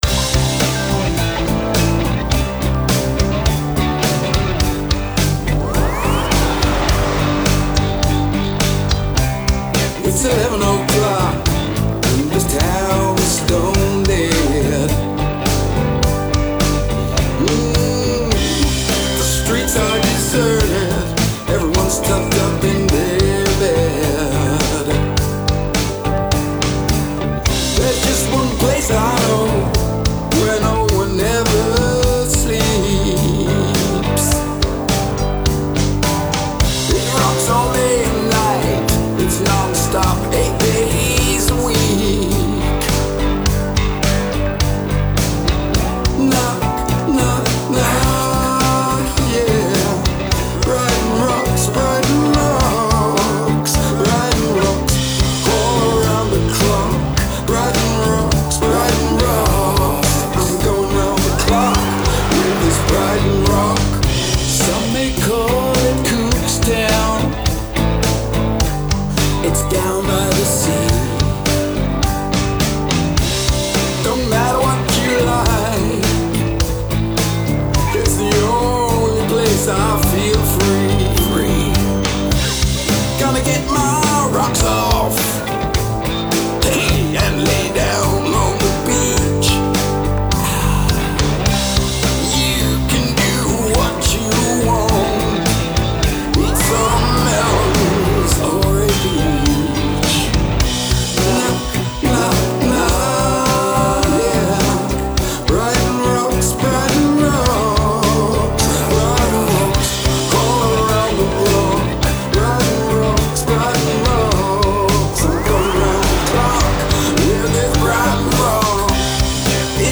two containing new Rock songs